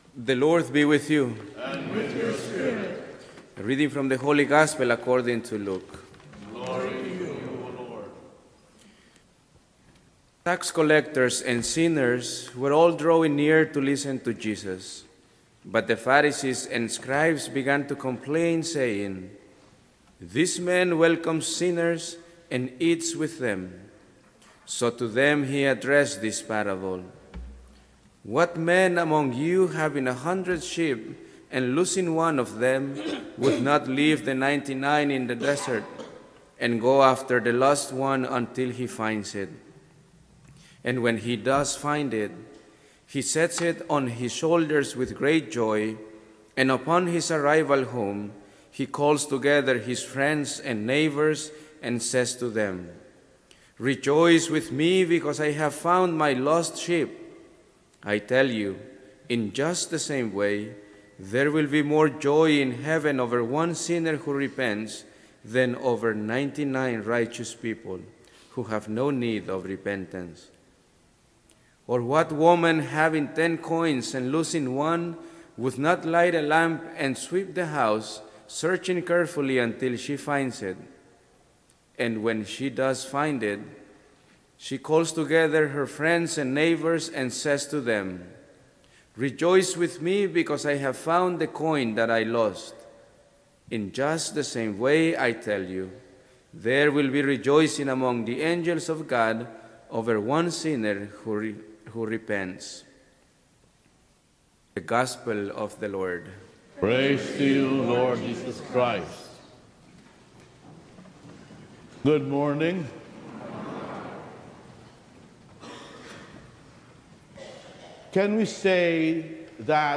Homily for Sunday September 15, 2019